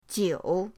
jiu3.mp3